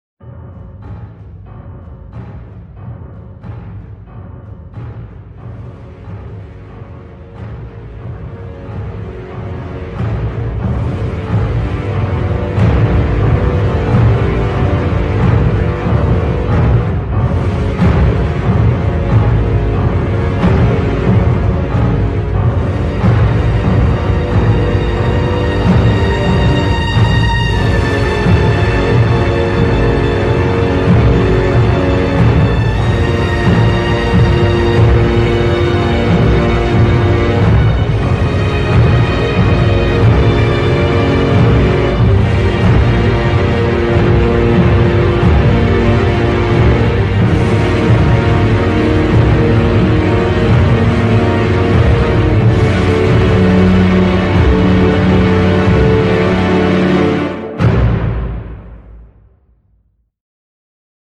War Sound -- Cinematic Sounds  [No Copyright Music].m4a